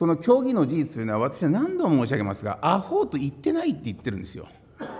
資料3　井桁議員が行った計4回の懲罰に対する弁明　音声⑥　（音声・音楽：63KB）